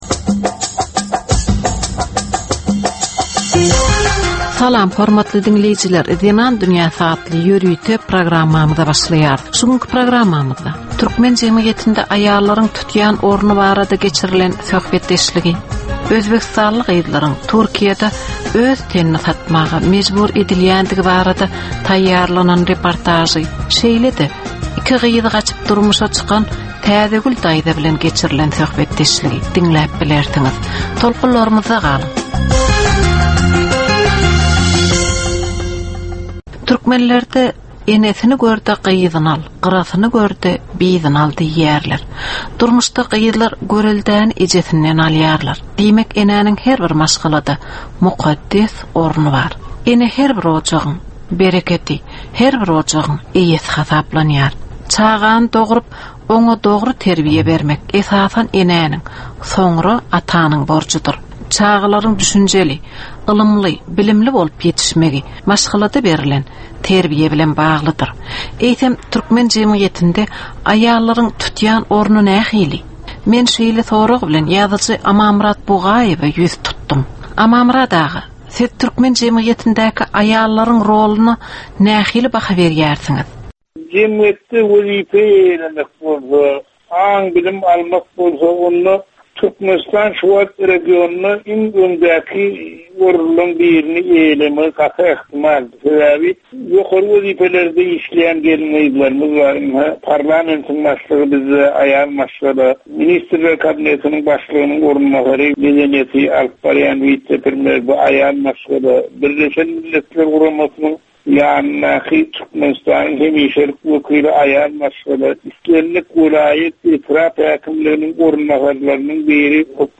Türkmen we halkara aýal-gyzlarynyň durmuşyna degişli derwaýys meselelere we täzeliklere bagyşlanylyp taýýarlanylýan 15 minutlyk ýörite gepleşik. Bu gepleşikde aýal-gyzlaryn durmuşyna degişli maglumatlar, synlar, bu meseleler boýunça synçylaryň we bilermenleriň pikirleri, teklipleri we diskussiýalary berilýär.